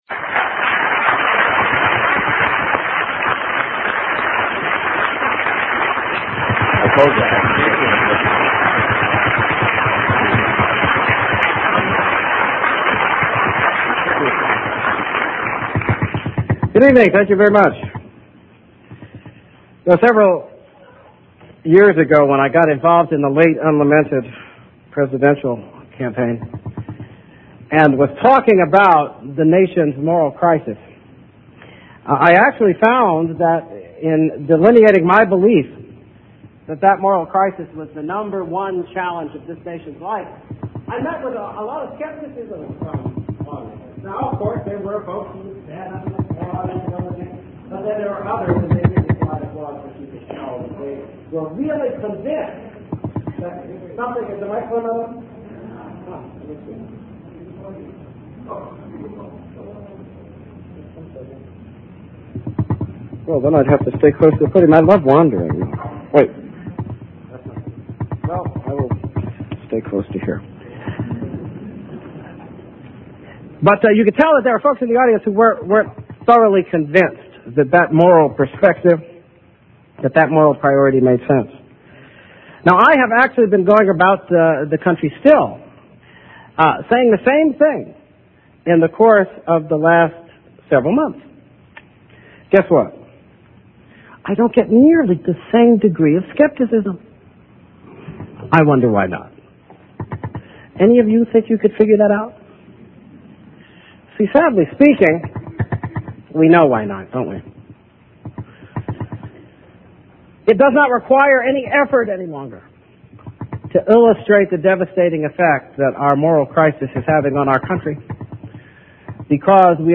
MP3 audio Speech Address to William Patterson University Alan Keyes December 11, 1998 Wayne, New Jersey Good evening.